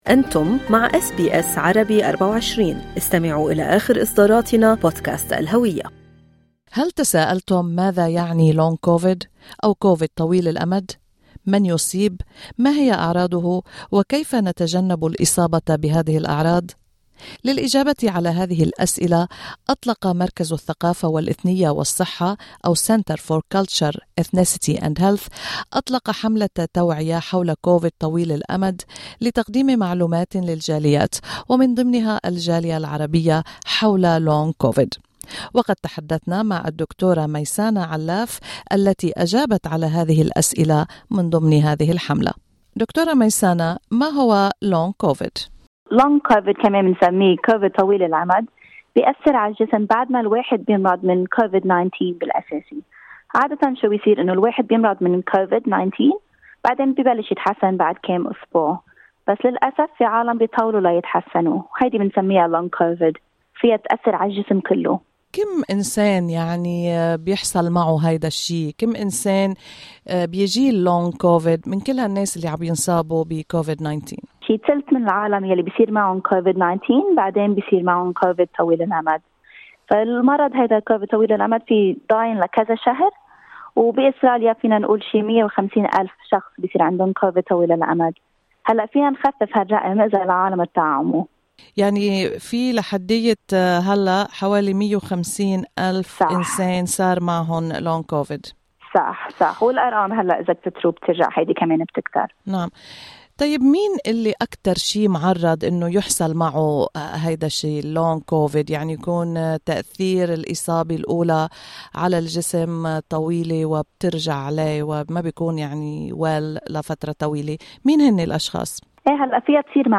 "من ضمنها النسيان والقلق": طبيبة تشرح أعراض كوفيد طويل الأمد ومن هم ضحاياه